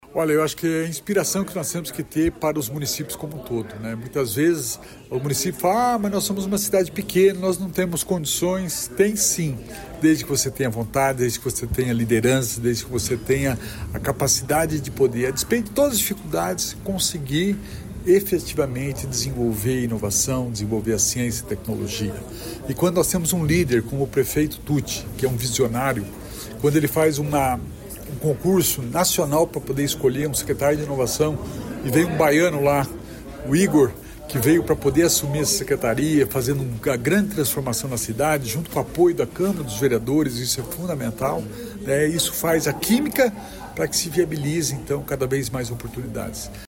Sonora do secretário da Inovação e Inteligência Artificial, Alex Canziani, sobre Assaí ser a 1ª da América Latina certificada como Comunidade Inteligente pelo Fórum Global